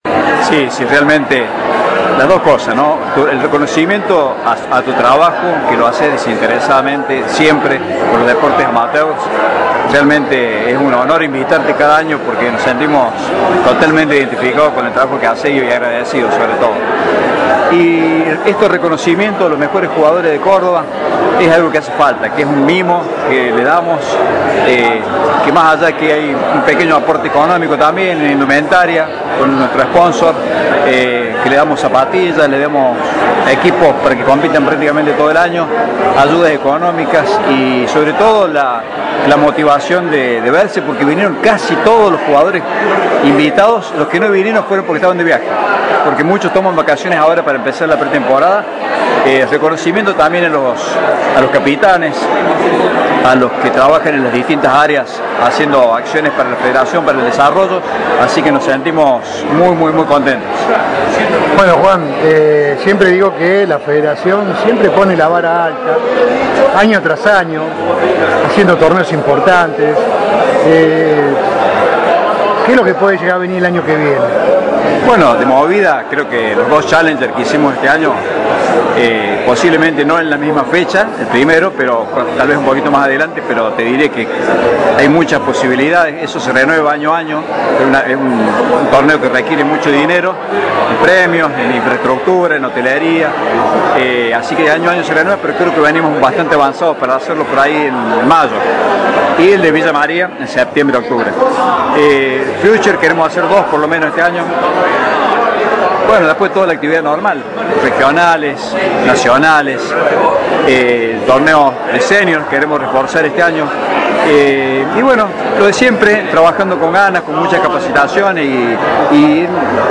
Audio nota